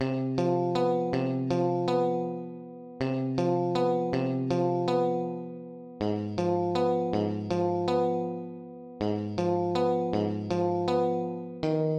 电吉他
Tag: 160 bpm Trap Loops Guitar Electric Loops 2.02 MB wav Key : Unknown FL Studio